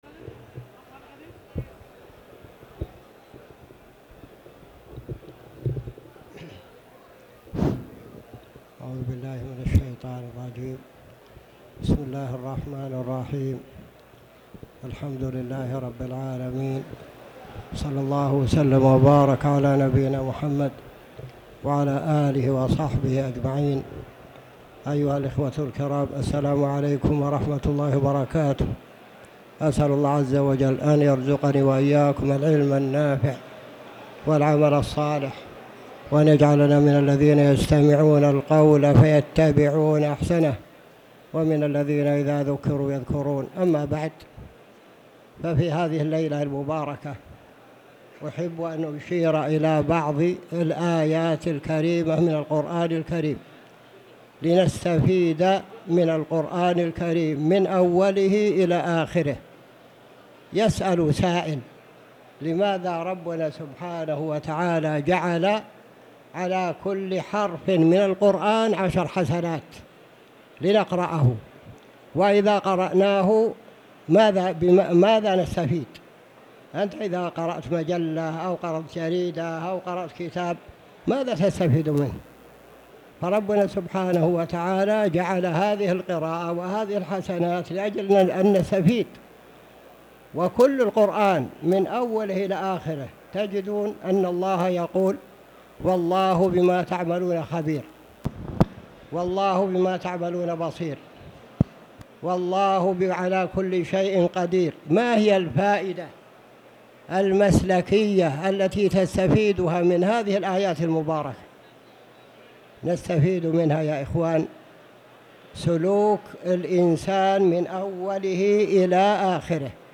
تاريخ النشر ٢٦ رمضان ١٤٣٩ هـ المكان: المسجد الحرام الشيخ